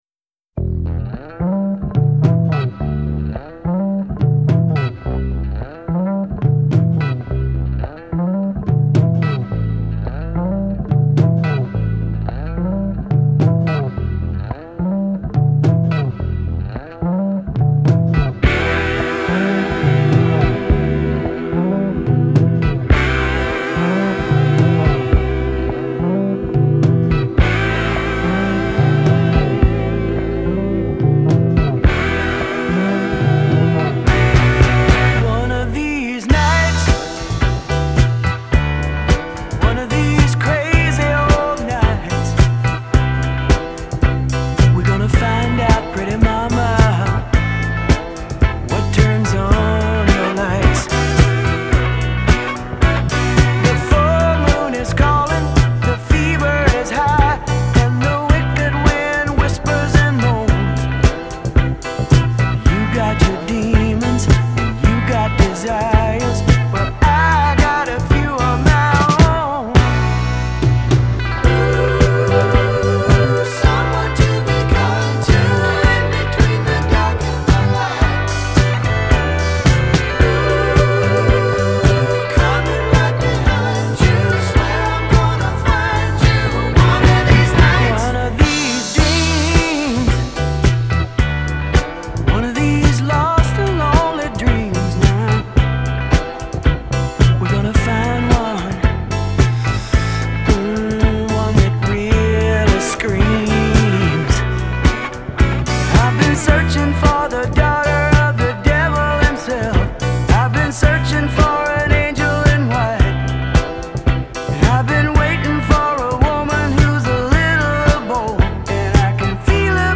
Live - 1977
рок-музыка